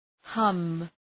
Προφορά
{hʌm}